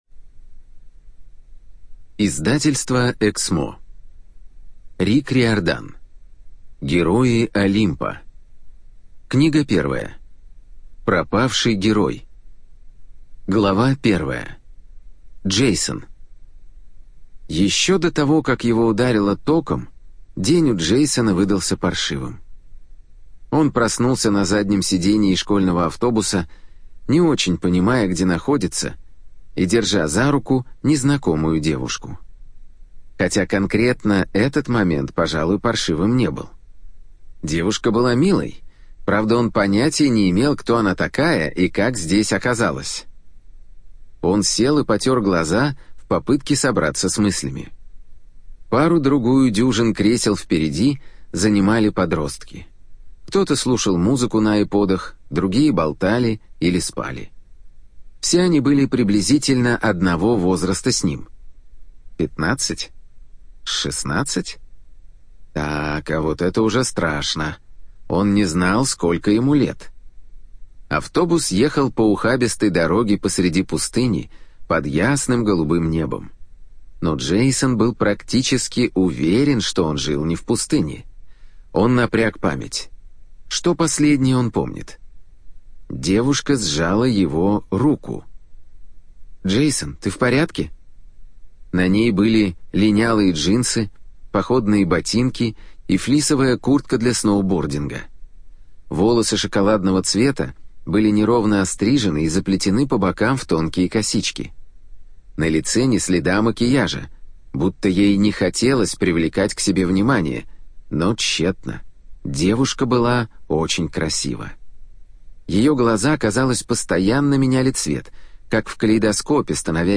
ЖанрДетская литература, Фэнтези
Студия звукозаписиЭКСМО